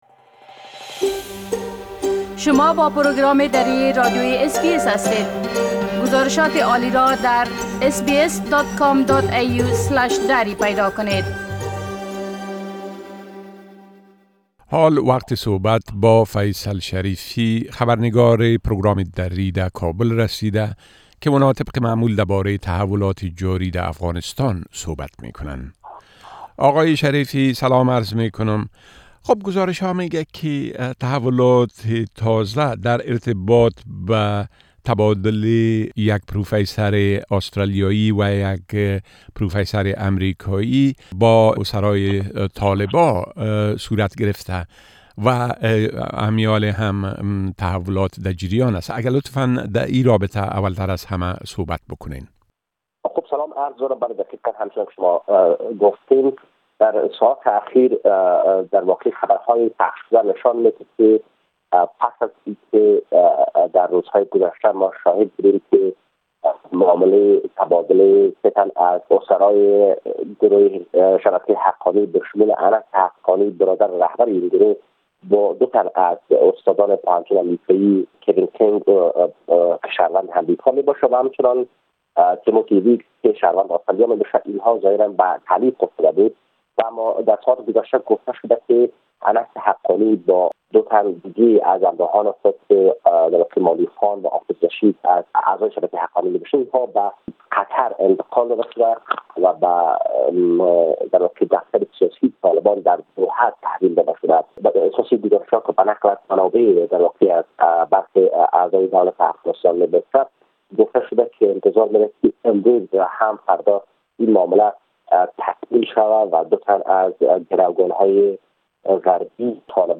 گرازش كامل خبرنگار ما در كابل به ادامۀ جنجالهاى انتخاباتى و رويداد هاى مهم ديگر در افغانستان را در اينجا شنيده ميتوانيد.